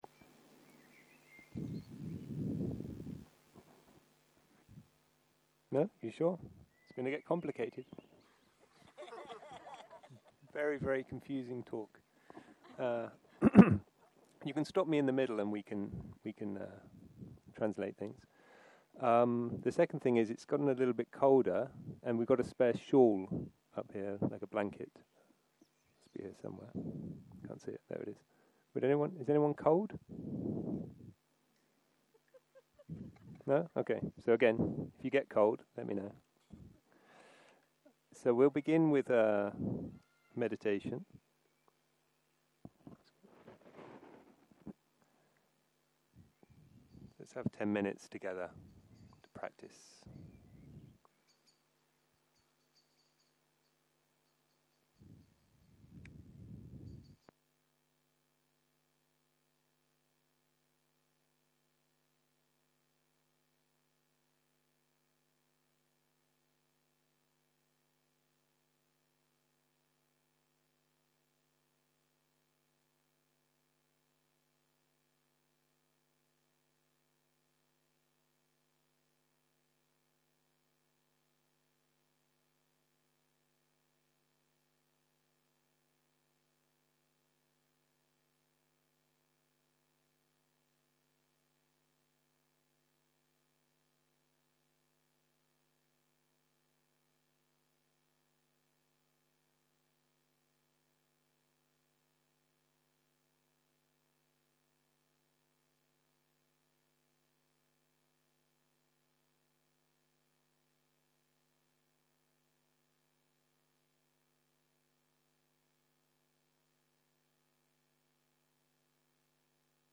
ערב - שיחת דהרמה - Seeing Waves